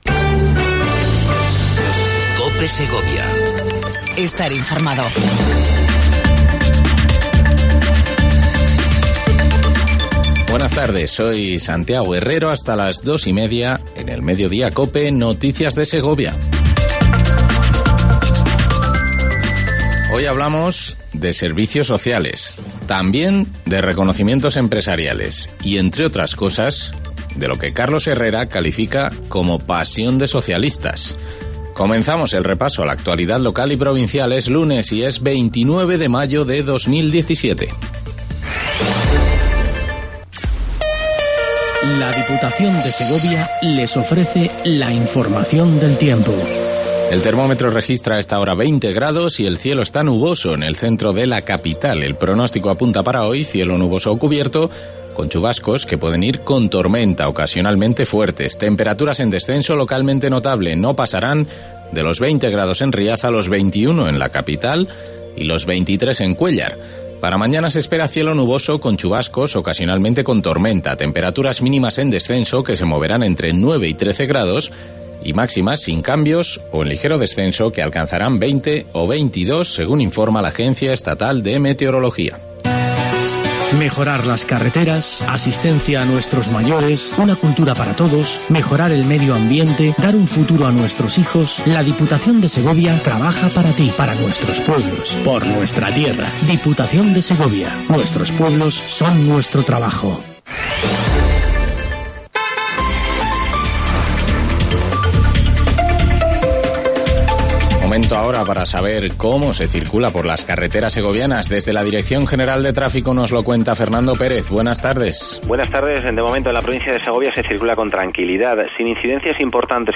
INFORMATIVO MEDIODIA COPE EN SEGOVIA 29 05 17